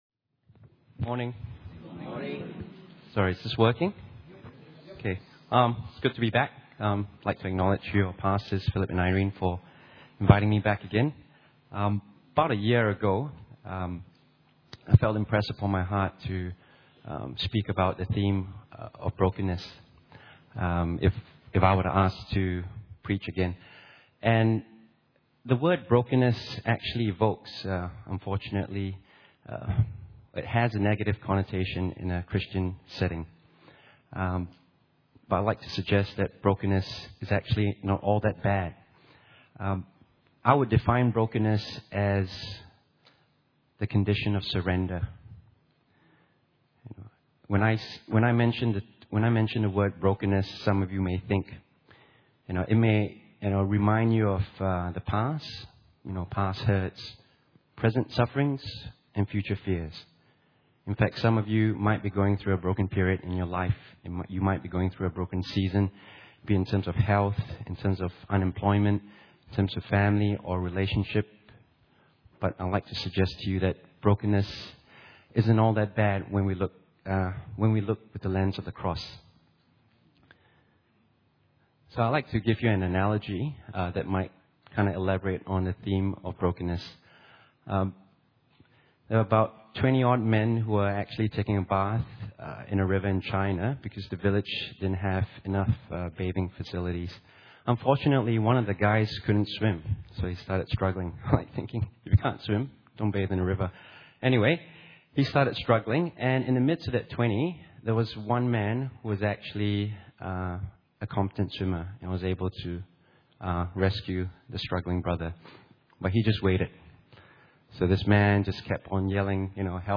Guest Speaker Service Type: Sunday Morning « The Power of Our Words SOM p13